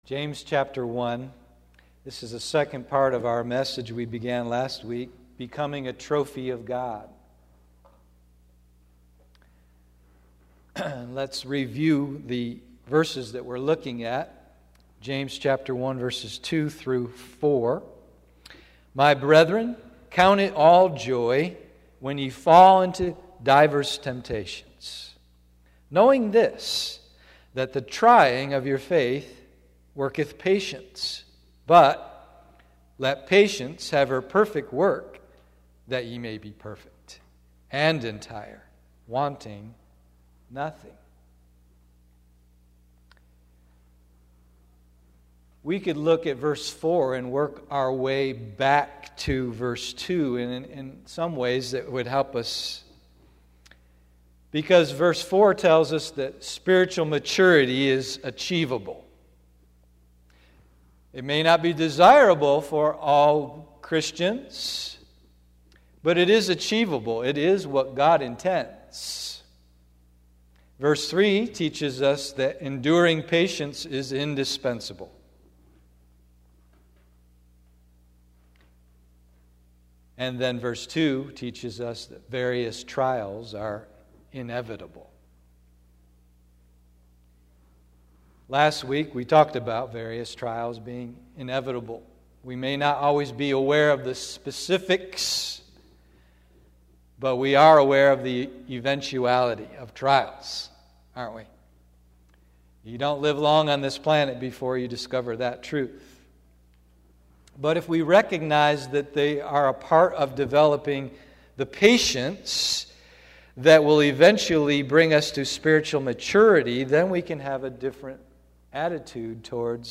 Becoming a Trophy of God, part 2, AM Service